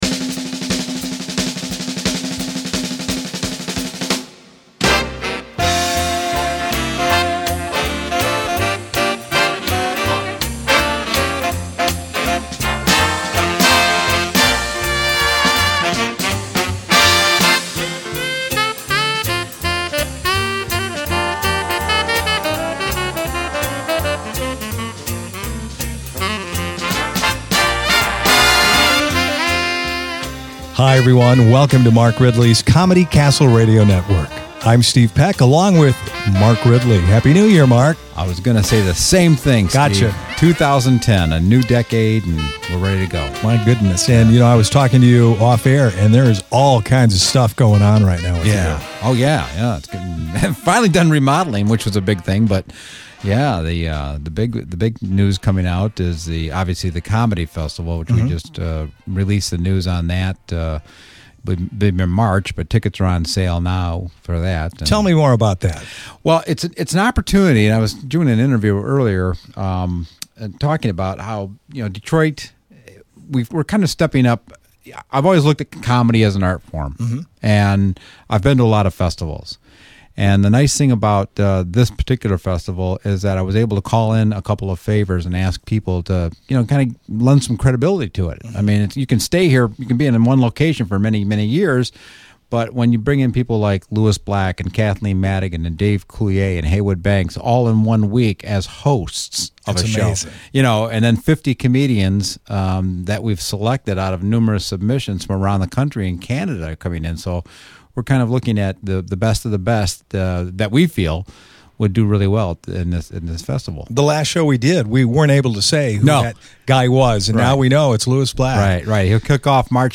Monthly message On-Hold Program This is your monthly Message On-Hold Program for Mark Ridley's Comedy Castle.